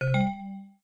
Windows Xp Usb In Téléchargement d'Effet Sonore
Windows Xp Usb In Bouton sonore